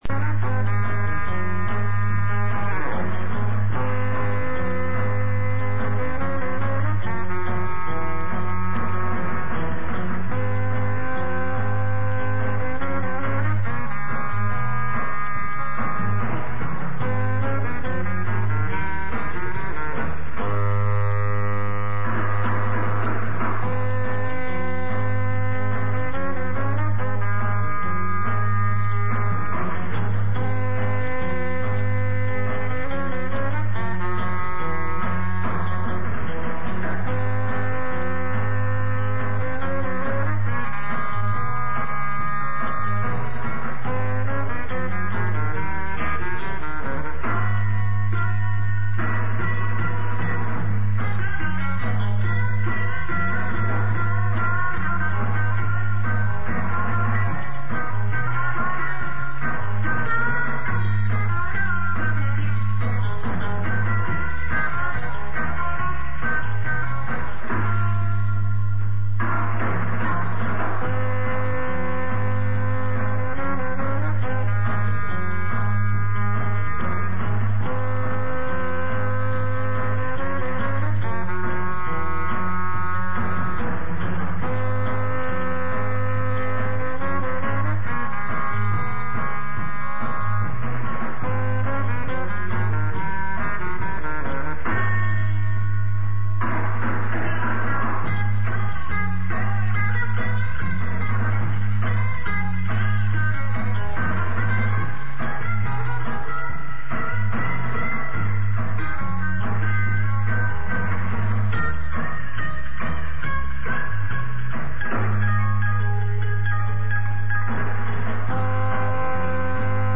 rehearsal
Instrumental